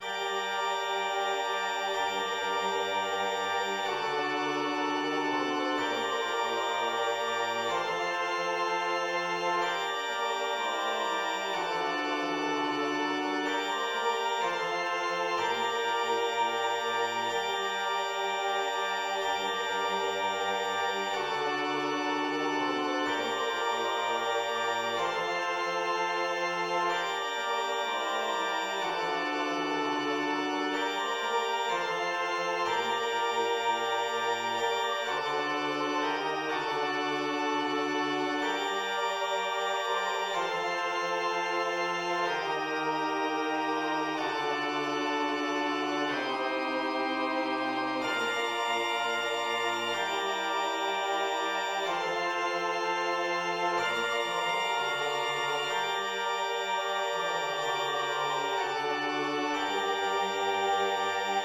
church organ
g1:m } \relative c'' { \time 4/4 \tempo 4=125 \key g \minor \set Staff.midiInstrument="choir aahs" \repeat volta 2 { g1